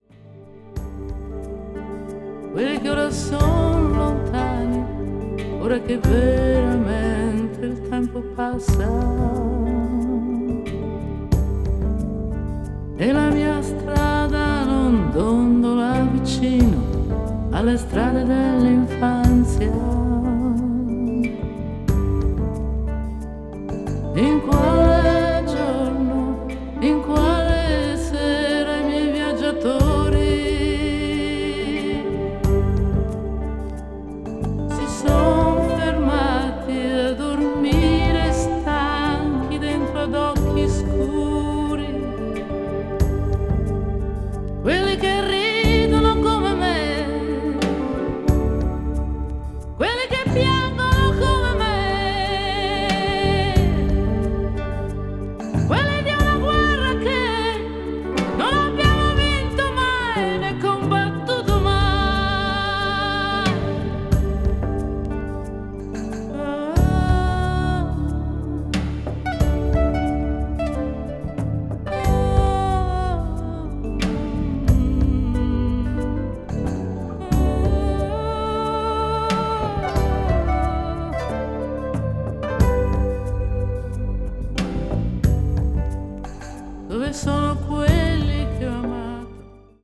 a.o.r.   adult pop   canciones   italian pop   mellow groove